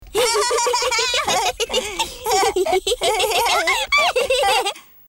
• Качество: высокое
Смех Пеппы с Джорджем